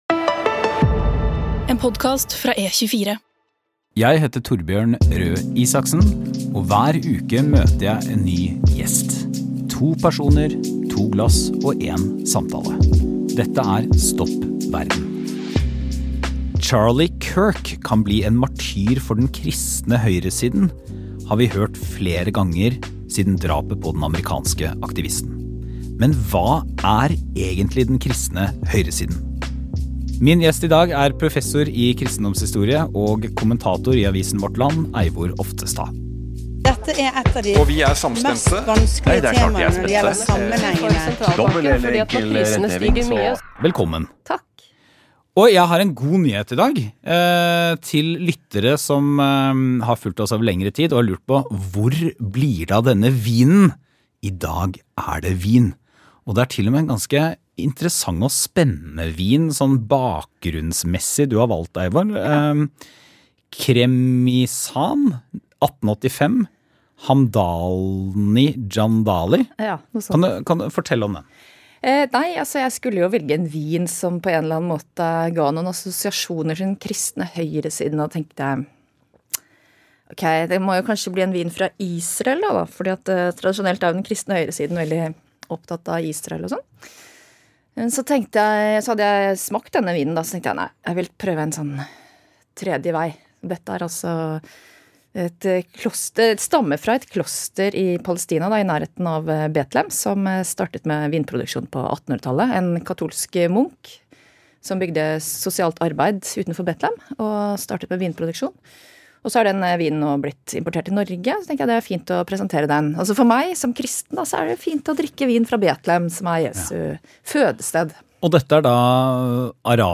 I vår hadde jeg livets første livepodkast på Exsalus treningsconvention i Mysen med disse 3 fine folkene.